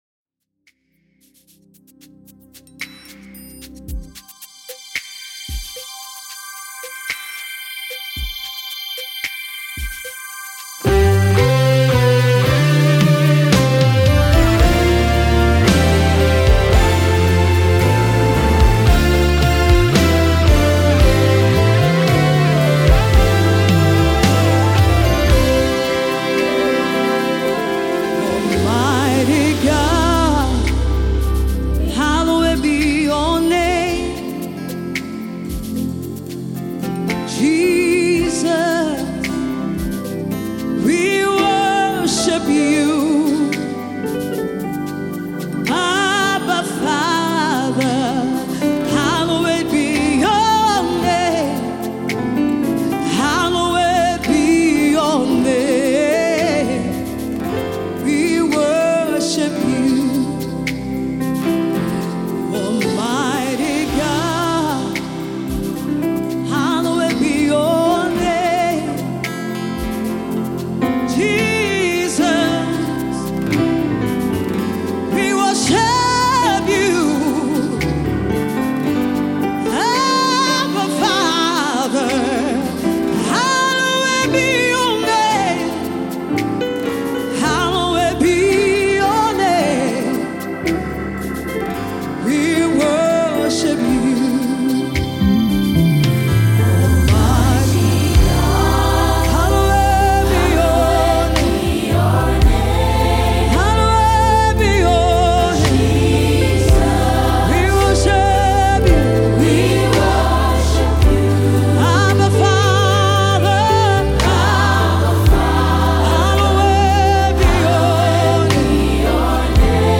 a new sound of worship